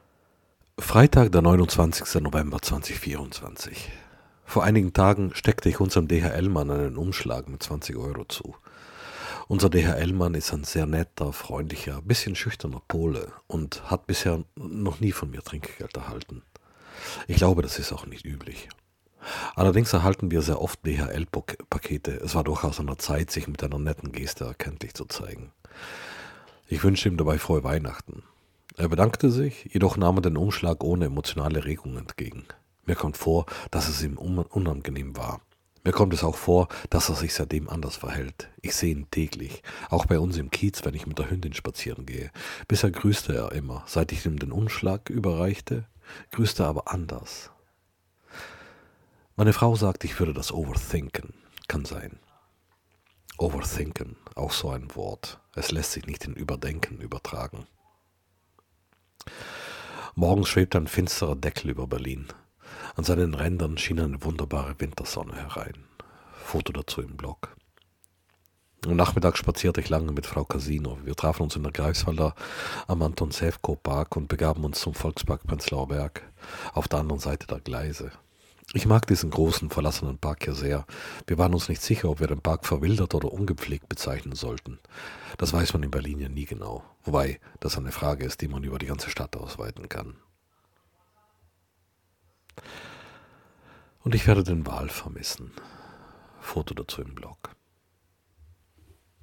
Ich lese Texte vor. Meist aus meinem Blog. Ab und spiele ich etwas dazu.